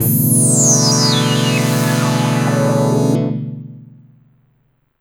46PAD 01  -L.wav